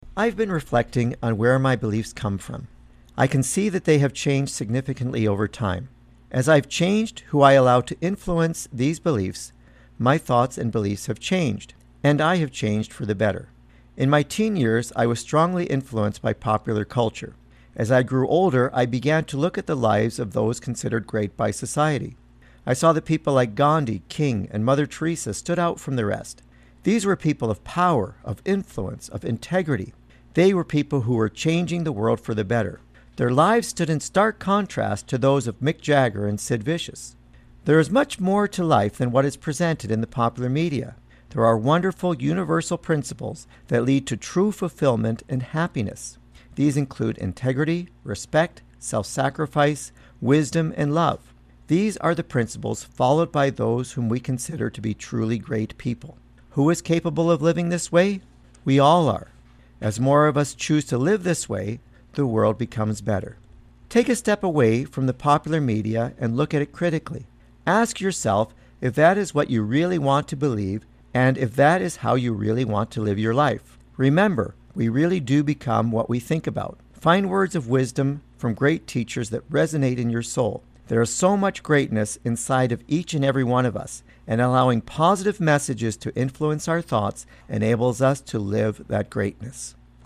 Recording Location: CFIS-FM, Prince George
Type: Commentary